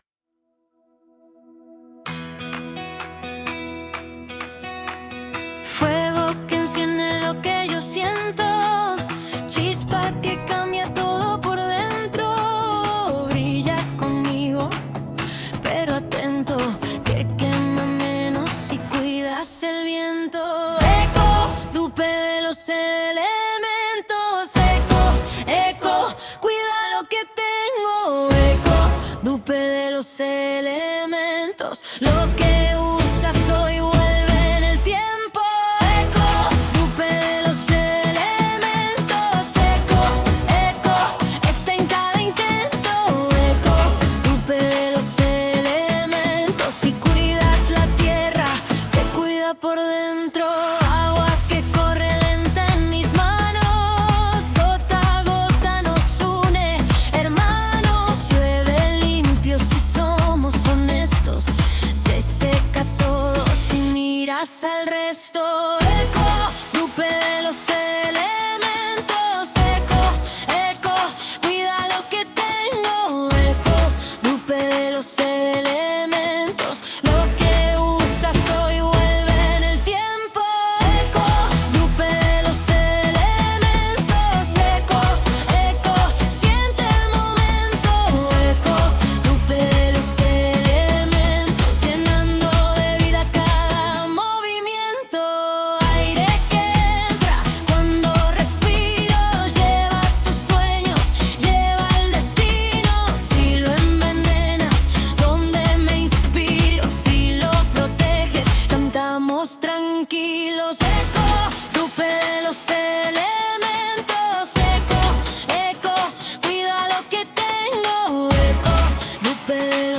CANCIONES PARA EL CARNAVAL
- Para el baile de alumnado.